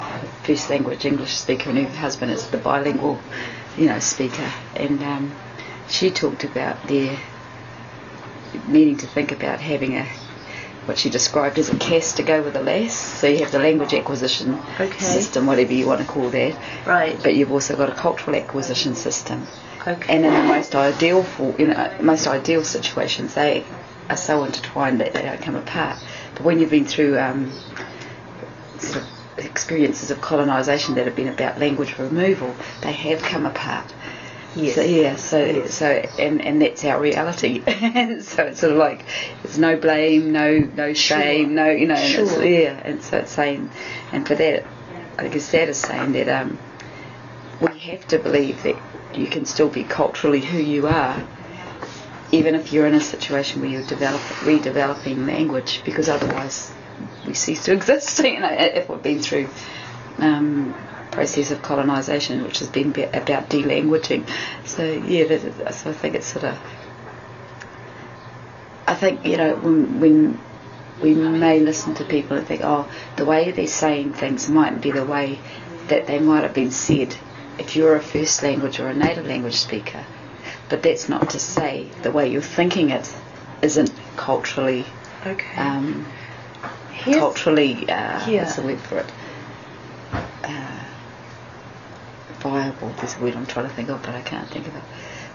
See below for a transcript of this interview: